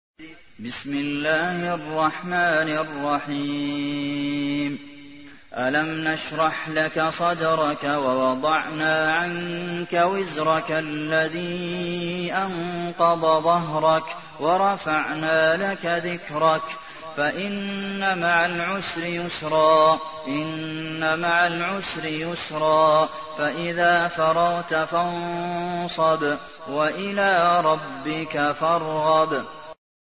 تحميل : 94. سورة الشرح / القارئ عبد المحسن قاسم / القرآن الكريم / موقع يا حسين